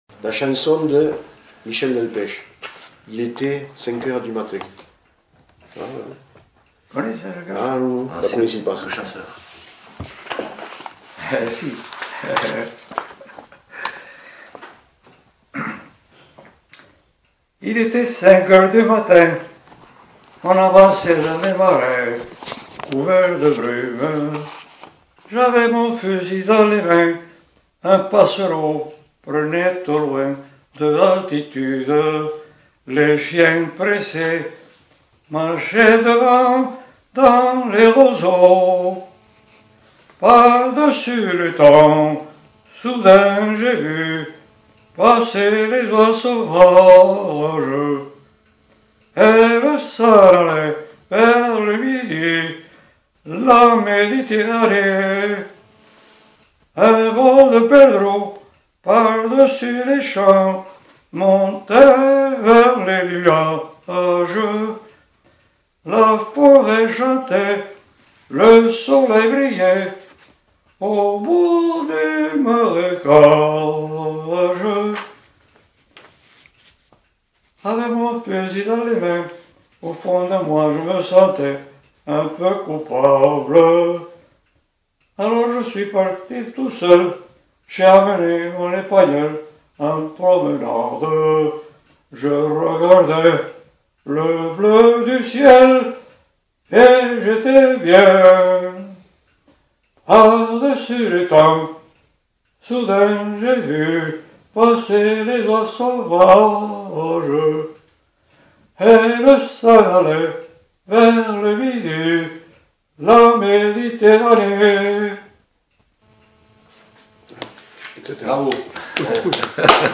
Lieu : Le Faget
Genre : chant
Effectif : 1
Type de voix : voix d'homme
Production du son : chanté